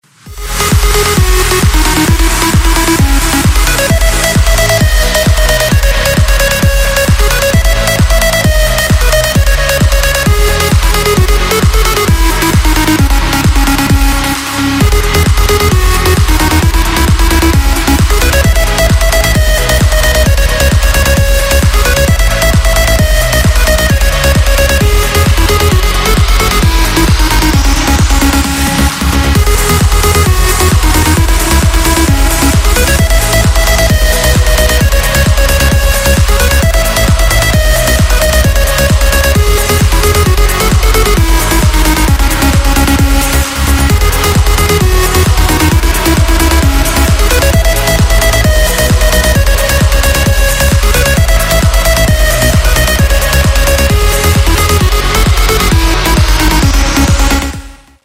громкие
Trance